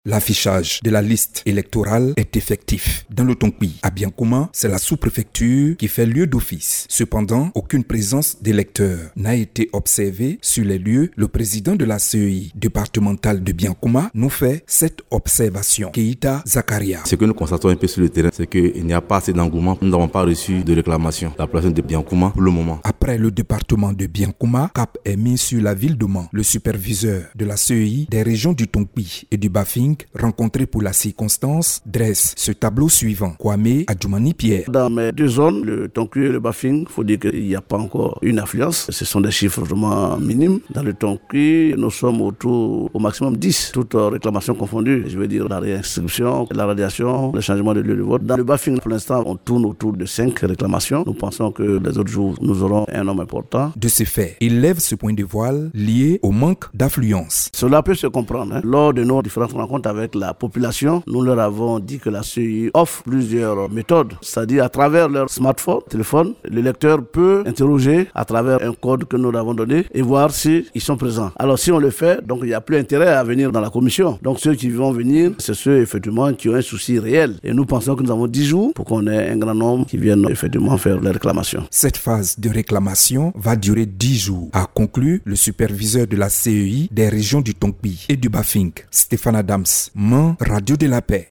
cei-contentieux-electoral-constat-apres-le-lancement-dans-le-tonkpi.mp3